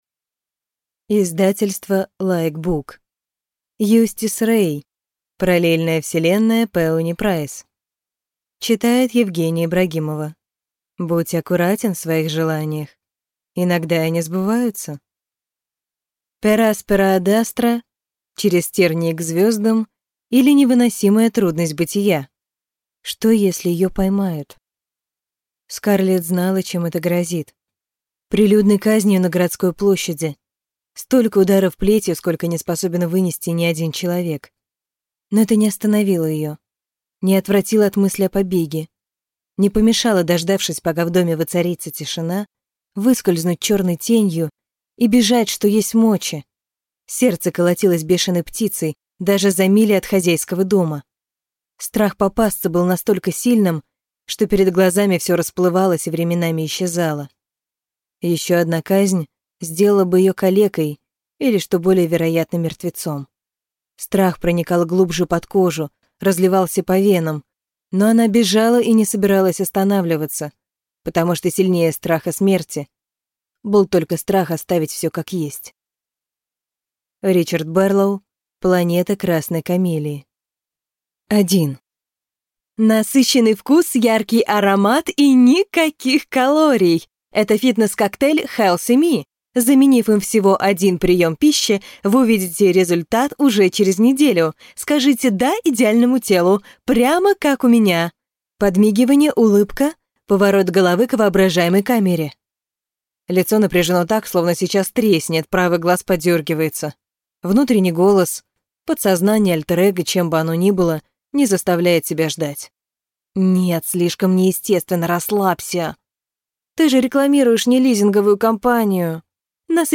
Аудиокнига Параллельная вселенная Пеони Прайс | Библиотека аудиокниг